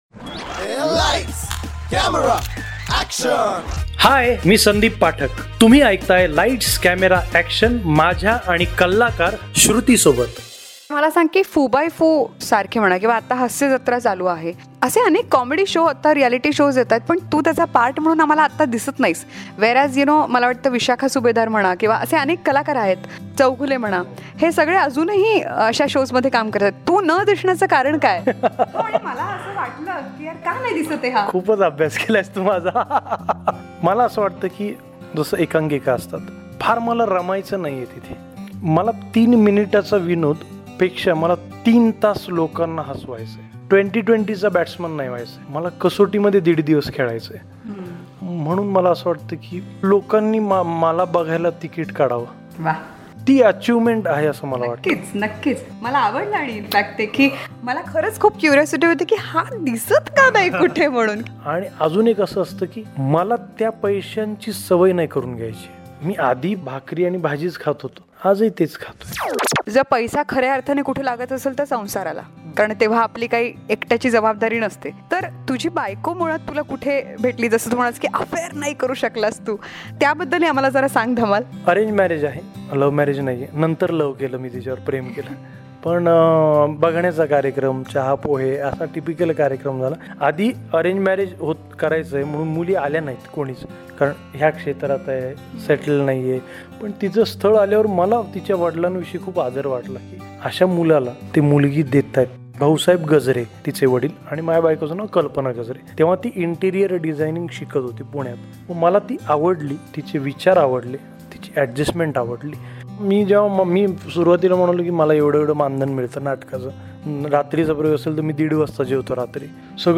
CONVERSATION WITH ACTOR & COMEDIAN SANDEEP PATHAK PART 5